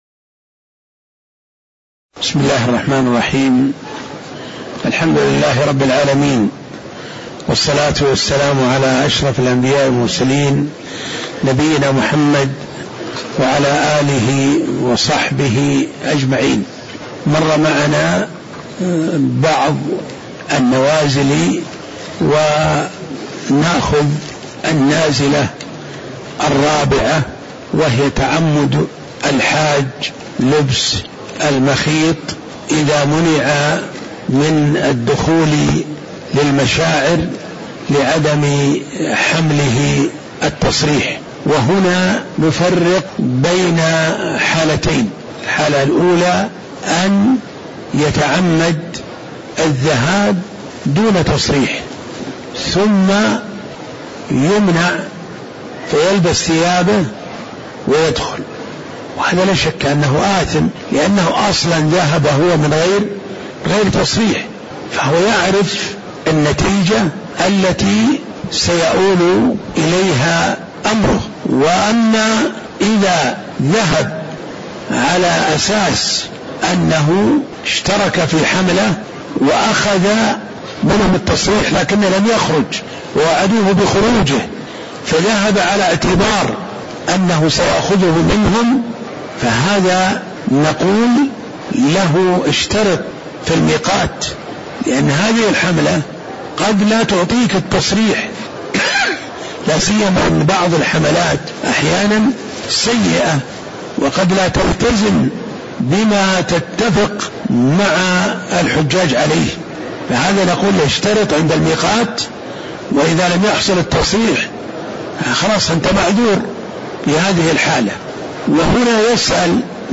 تاريخ النشر ٢٥ ذو القعدة ١٤٣٩ هـ المكان: المسجد النبوي الشيخ: عبدالله بن محمد الطيار عبدالله بن محمد الطيار النازلة الرابعة تعمّد الحاج لبس المخيط (02)